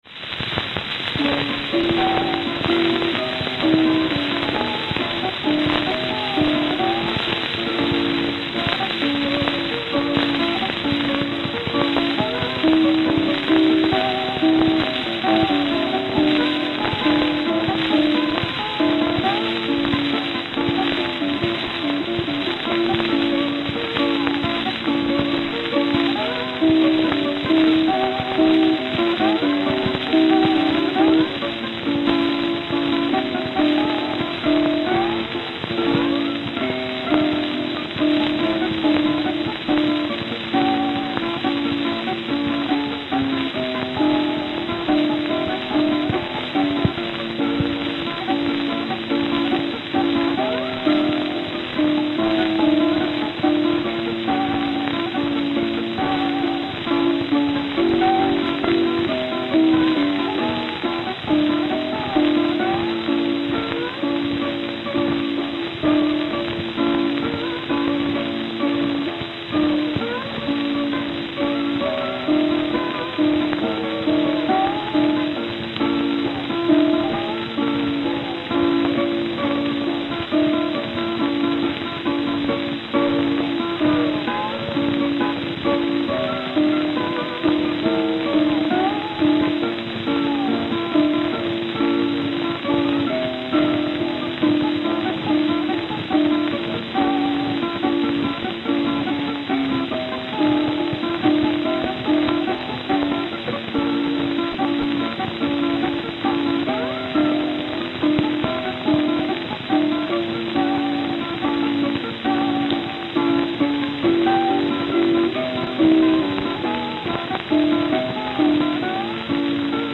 Note: Extremely worn.